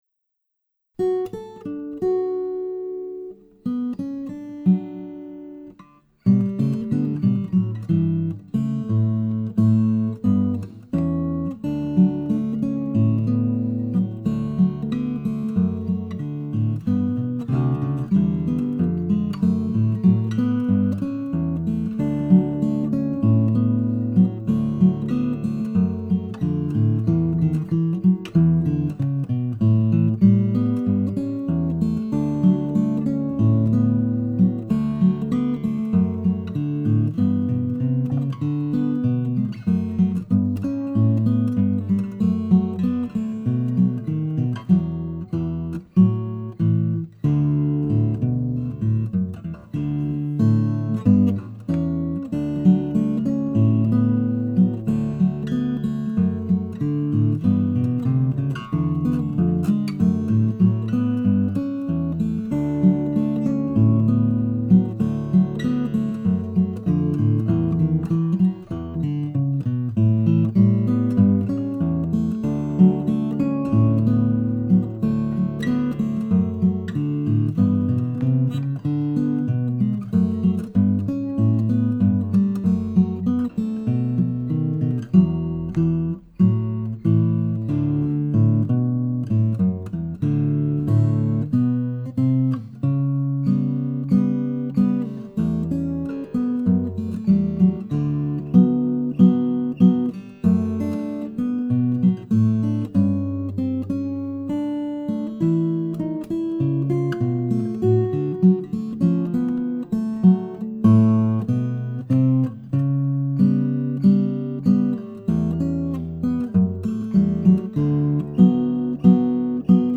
DIGITAL SHEET MUSIC - FINGERPICKING SOLO